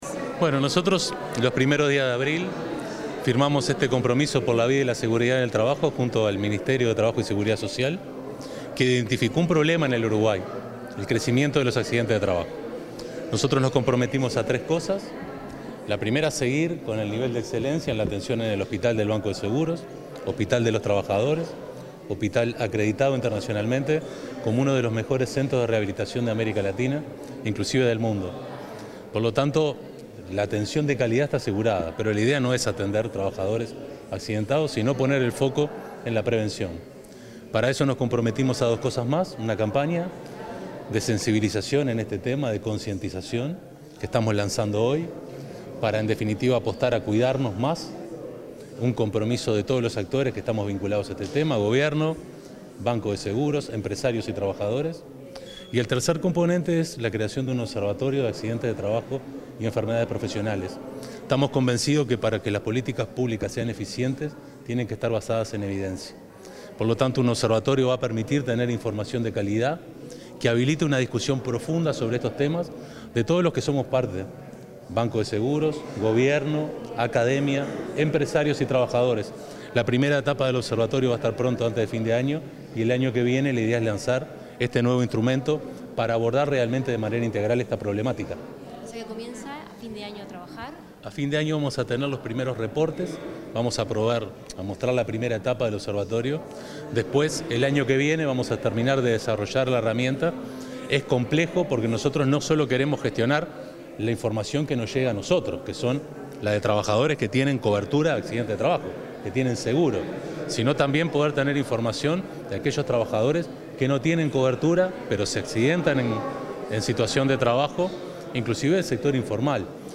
Declaraciones del presidente del Banco de Seguros del Estado, Marcos Otheguy